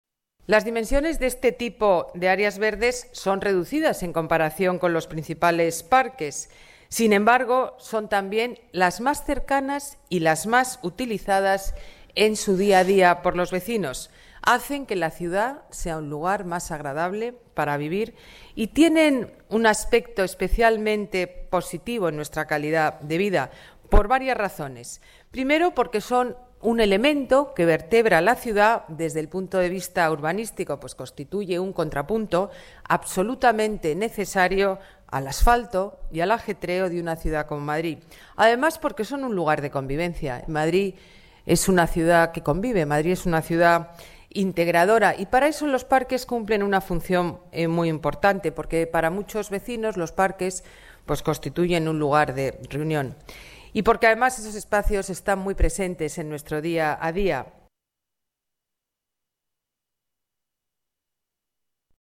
Nueva ventana:Declaraciones alcaldesa Madrid, Ana Botella: plan renovación zonas verdes de barrio, presupuesto